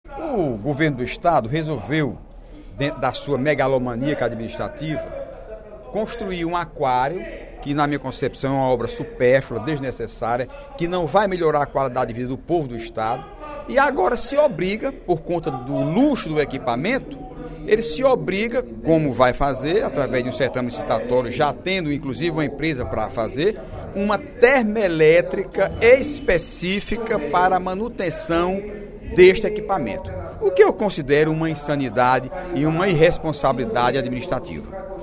No primeiro expediente da sessão plenária desta terça-feira (03/12), o deputado Heitor Férrer (PDT) criticou a construção de uma usina termelétrica a gás para abastecer o Acquario do Ceará. O deputado acredita que o investimento deveria ser direcionado às universidades estaduais.